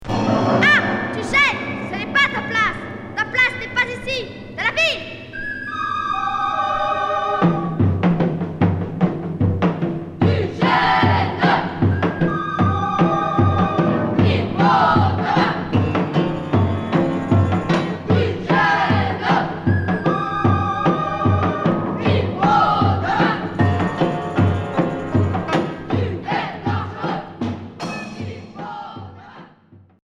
Expérimental enfantin